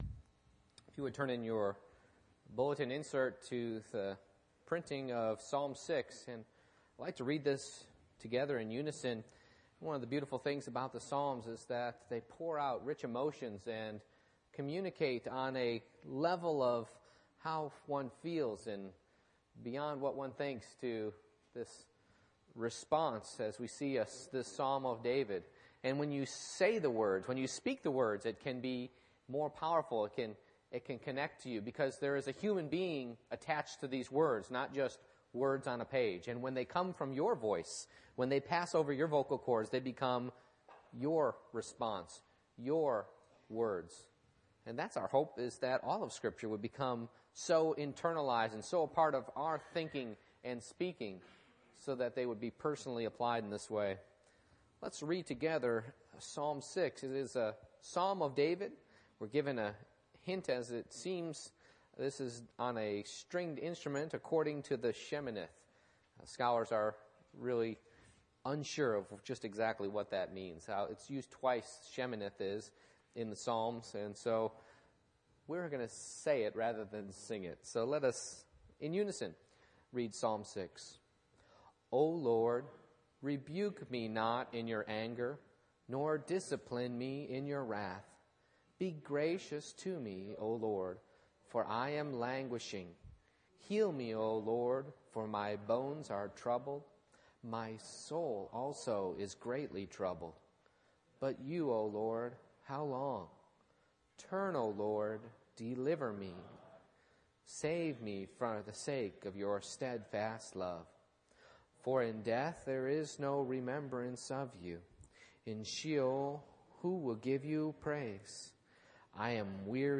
Psalm 6:1-10 Service Type: Morning Worship I. Depression Is a Sin Problem 1-3